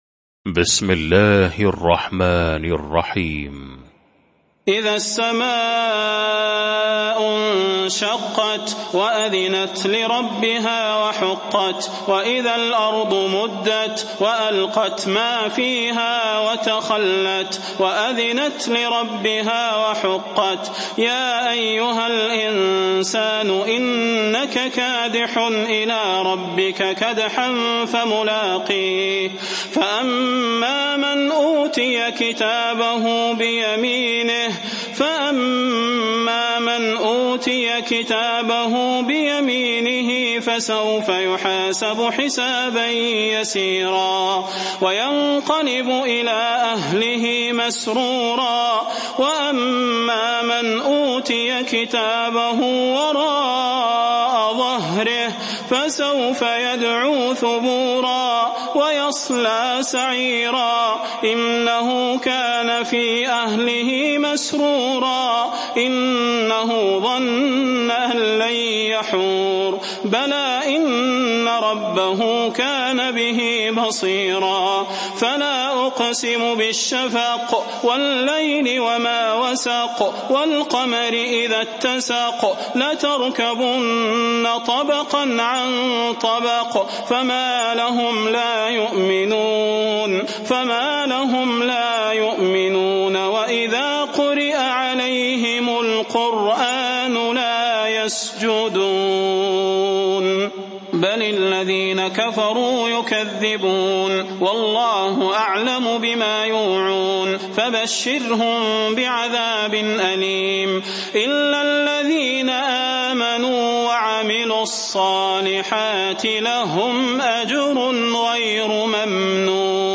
المكان: المسجد النبوي الشيخ: فضيلة الشيخ د. صلاح بن محمد البدير فضيلة الشيخ د. صلاح بن محمد البدير الانشقاق